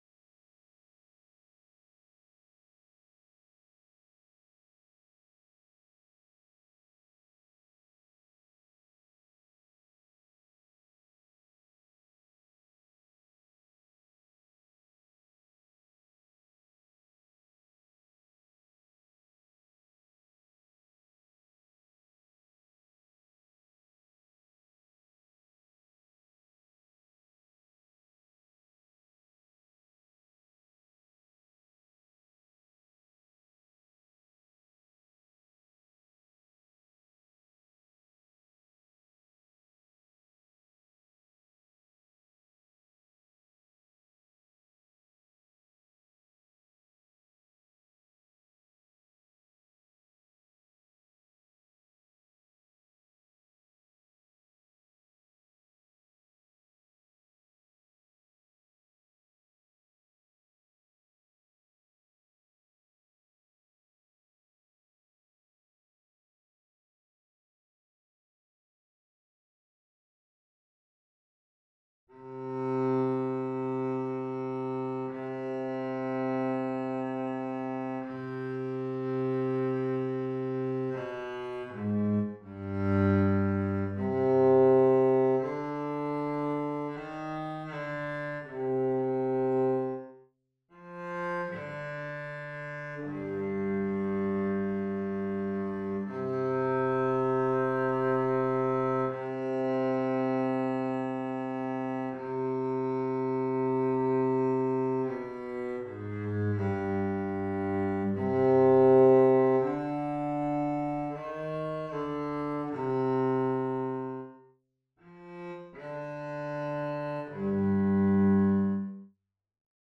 11. Violoncello (Cello/Auto)
Kopiya-gloria-di-sol-2-Double2-07-Cello_0.mp3